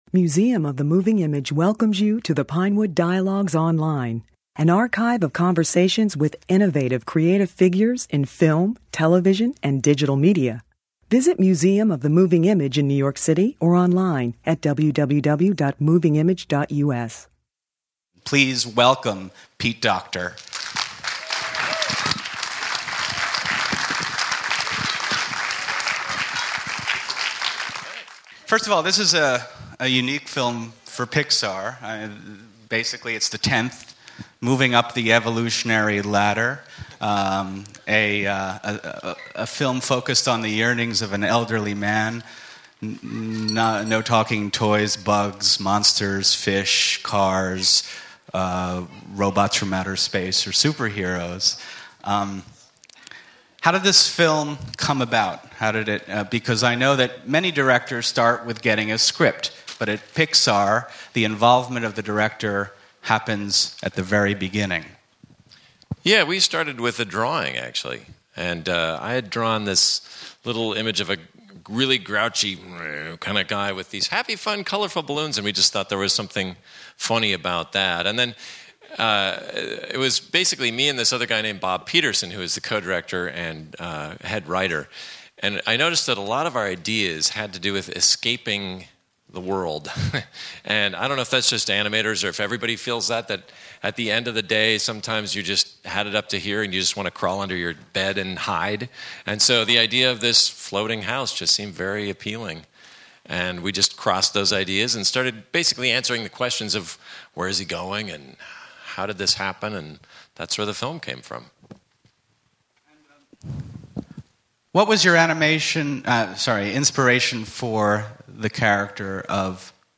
Pete Docter, the director of the Disney/Pixar movie UP, is one of the top creative figures in contemporary animation. He directed Monsters, Inc. and was a writer for Toy Story, Toy Story 2, and Wall-E. Docter spoke at a Museum of the Moving Image event following a preview screening for the Museum's new family member group Red Carpet Kids. The discussion includes questions from some of the young children in the audience.